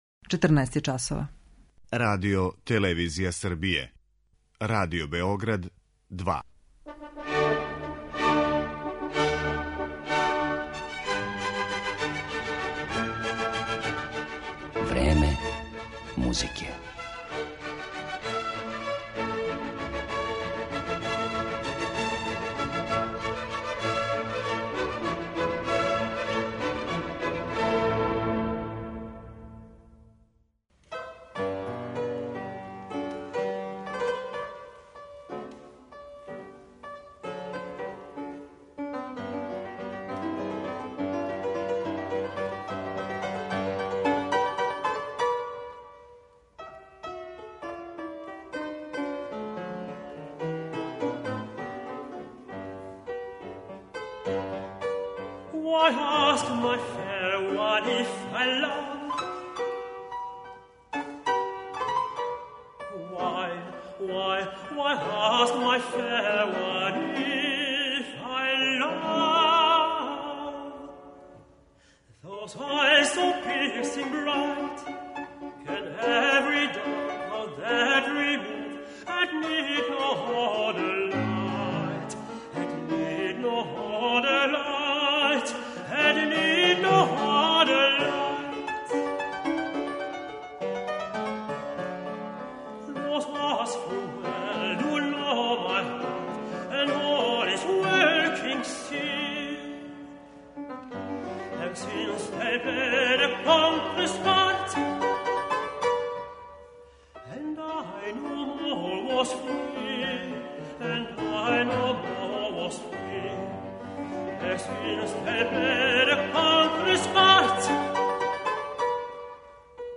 Јозеф Хајдн: Песме за глас и клавир
белгијски тенор
бугарског пијанисту